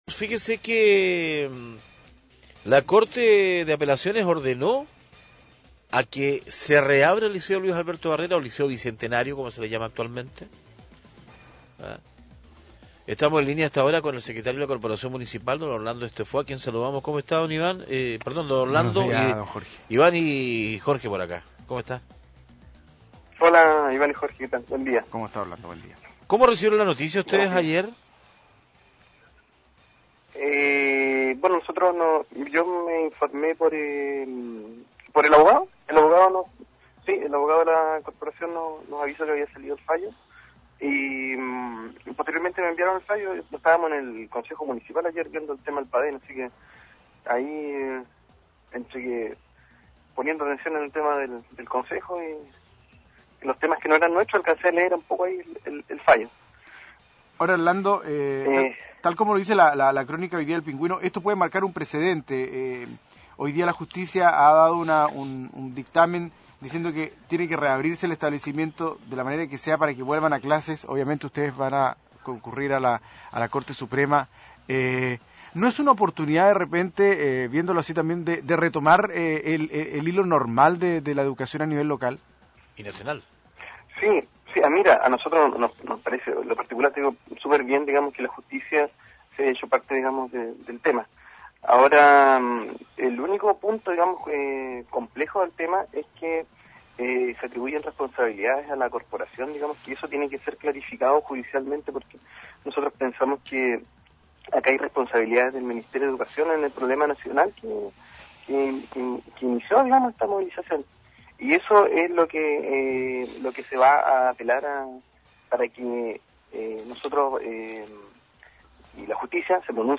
Entrevistas de Pingüino Radio - Diario El Pingüino - Punta Arenas, Chile
Pedro Muñoz, senador PS